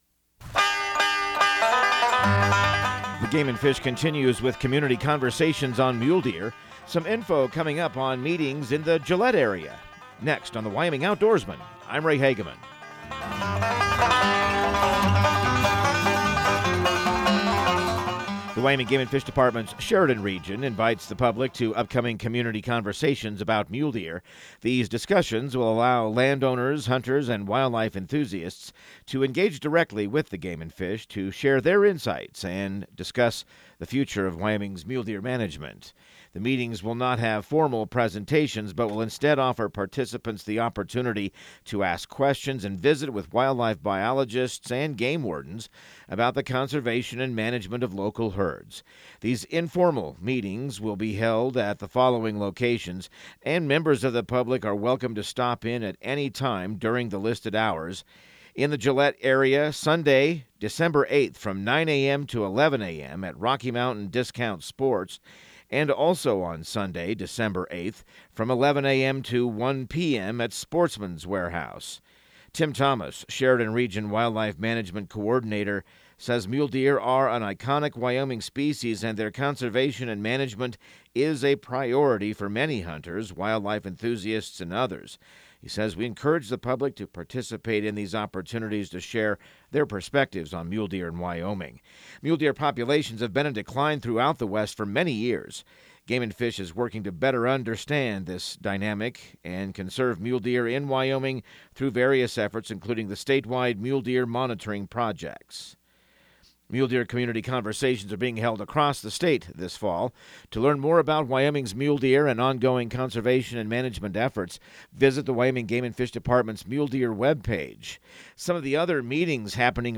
Radio news | Week of November 25